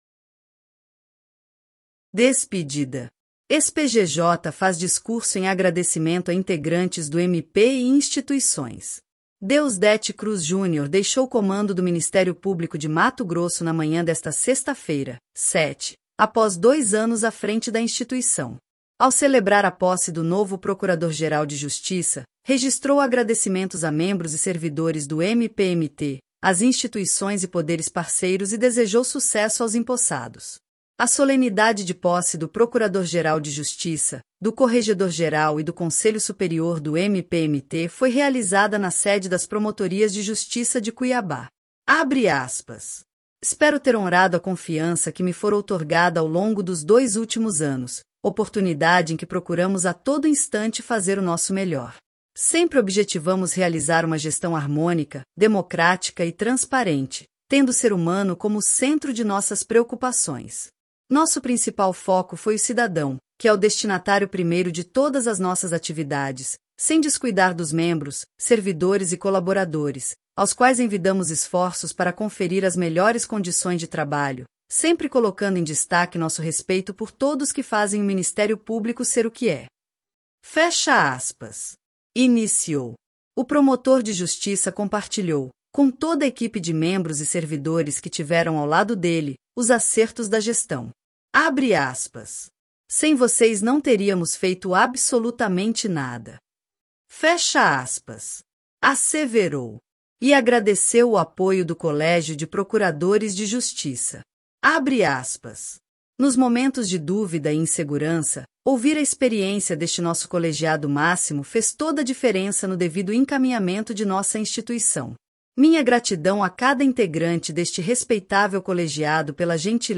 Ex-PGJ faz discurso em agradecimento a integrantes do MP e instituições
Ao celebrar a posse do novo procurador-geral de Justiça, registrou agradecimentos a membros e servidores do MPMT, às instituições e poderes parceiros e desejou sucesso aos empossados. A solenidade de posse do procurador-geral de Justiça, do corregedor-geral e do Conselho Superior do MPMT foi realizada na sede das Promotorias de Justiça de Cuiabá.